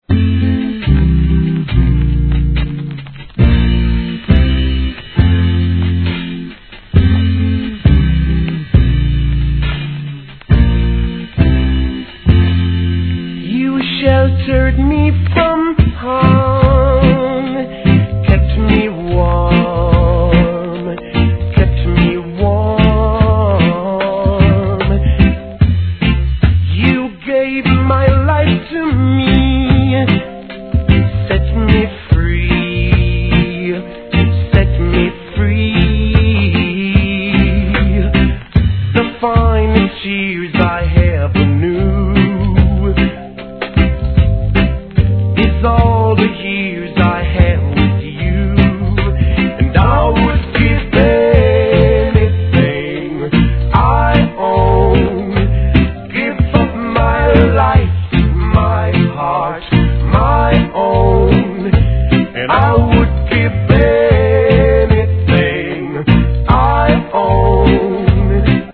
(頭にノイズ)
REGGAE